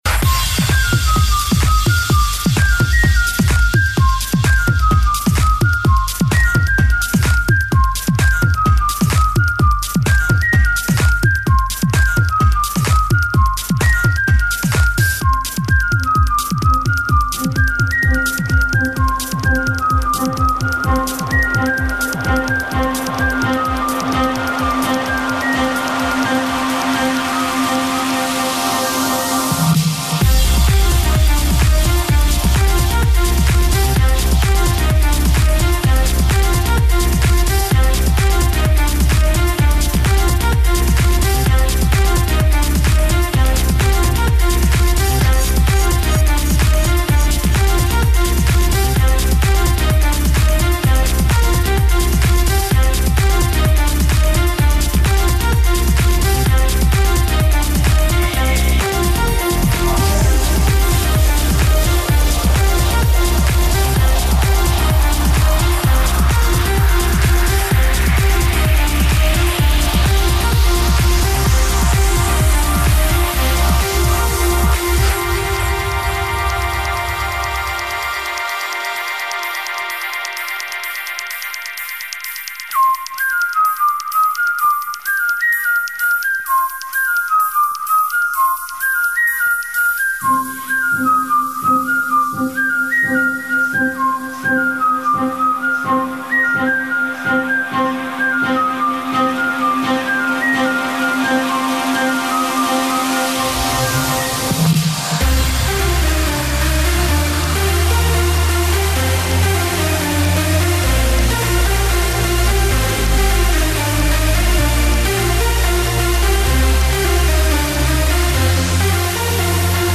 Категория: Клубняк